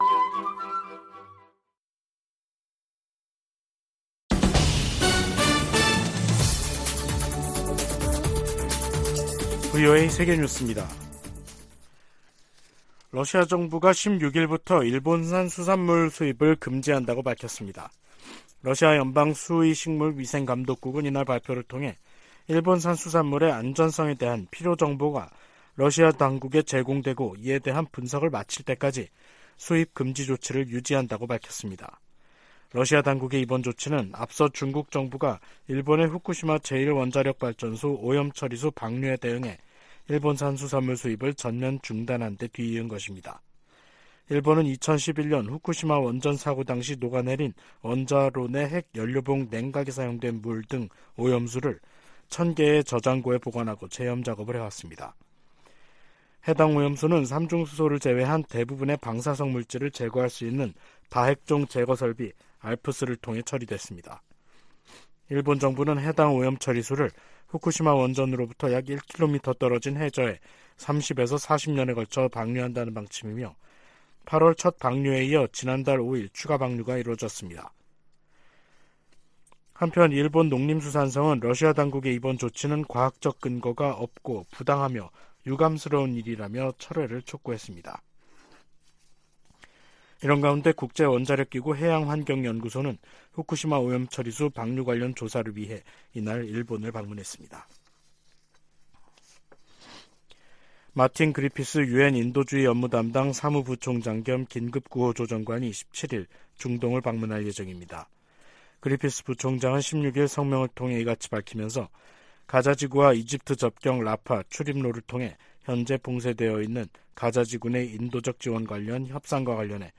VOA 한국어 간판 뉴스 프로그램 '뉴스 투데이', 2023년 10월 16일 3부 방송입니다. 북한이 지난달 컨테이너 1천개 분량의 군사장비와 탄약을 러시아에 제공했다고 백악관이 밝혔습니다. 미국 정부가 북러 무기 거래 현장으로 지목한 항구에서 계속 선박과 컨테이너의 움직임이 포착되고 있습니다. 줄리 터너 미 국무부 북한인권특사가 한국을 방문해 북한 인권 상황을 개선하기 위해 국제사회가 힘을 합쳐야 한다고 강조했습니다.